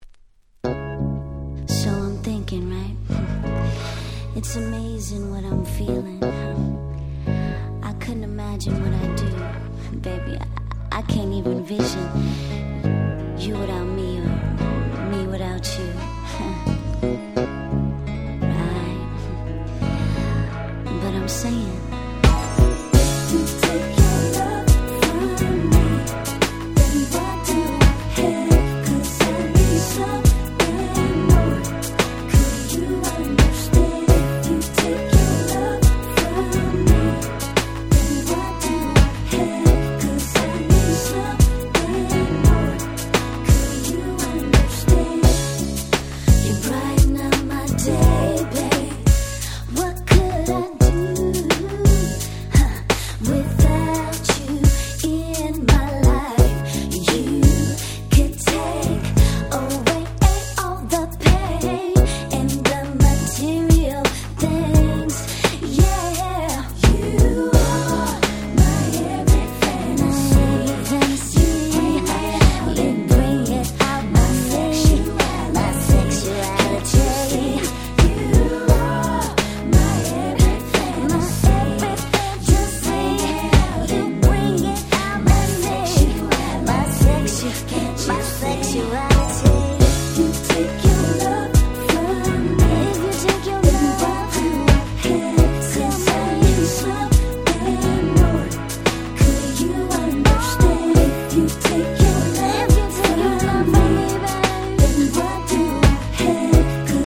96' Nice R&B / Hip Hop Soul !!
いぶし銀ながら雰囲気のあるナイスなHip Hop Soul !!
シブくて良いです！！